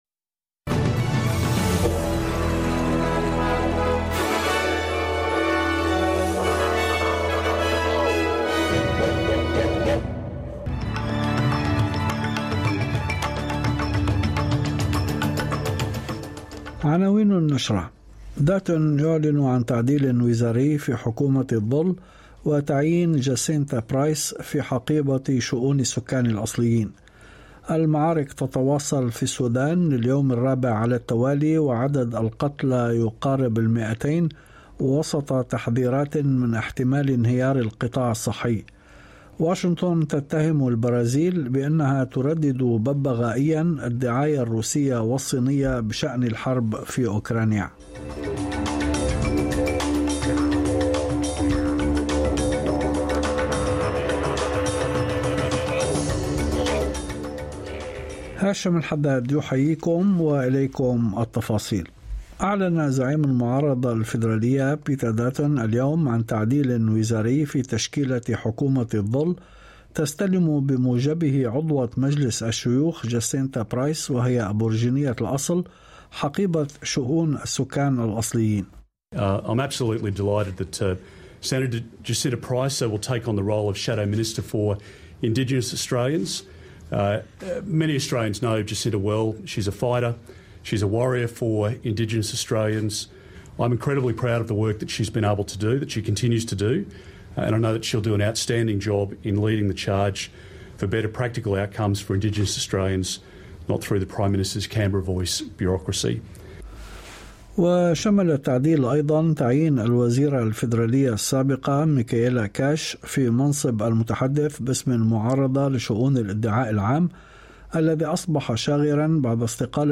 نشرة أخبار المساء 18/04/2023